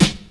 • Old School Hip-Hop Snare One Shot B Key 20.wav
Royality free snare one shot tuned to the B note. Loudest frequency: 1961Hz
old-school-hip-hop-snare-one-shot-b-key-20-J4f.wav